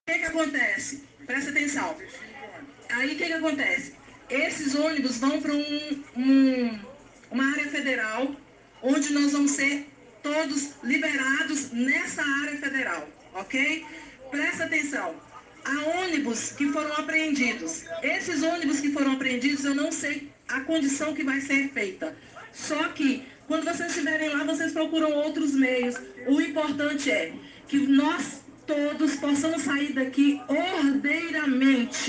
A disparidade mais significativa é na pronúncia da letra “R”: a manifestante golpista prolonga mais o som da letra do que a ex-candidata (ouça a comparação abaixo).